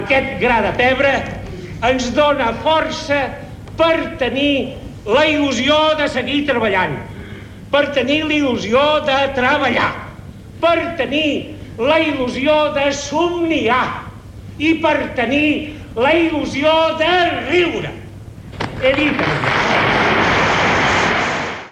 Final del discurs de l'escriptor Josep Maria de Sagarra en l'Homentage a Santiago Rusiñol celebrat al Windsor Palace de Barcelona.
Extret de Crònica Sentimental de Ràdio Barcelona emesa el dia 22 d'octubre de 1994.